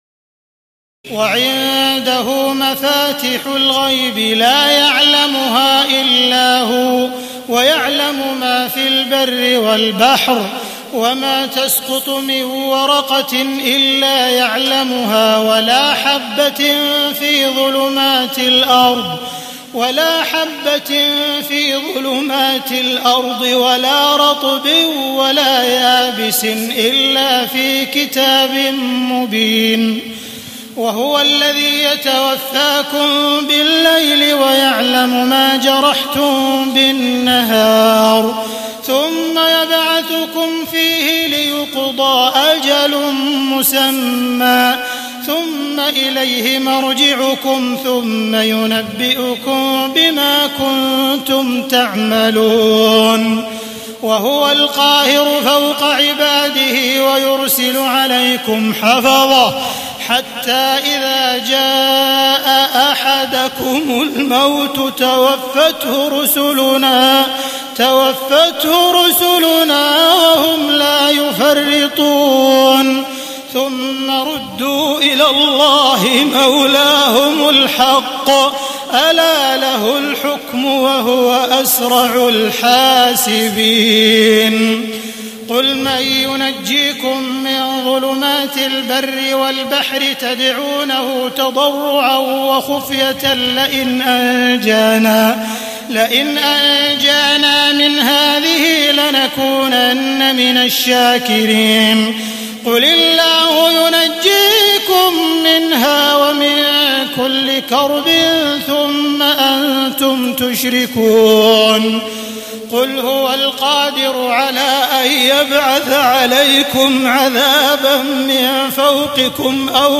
تهجد ليلة 27 رمضان 1432هـ من سورة الأنعام (59-111) Tahajjud 27 st night Ramadan 1432H from Surah Al-An’aam > تراويح الحرم المكي عام 1432 🕋 > التراويح - تلاوات الحرمين